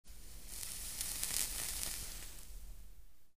Звук затягивания дыма сигареты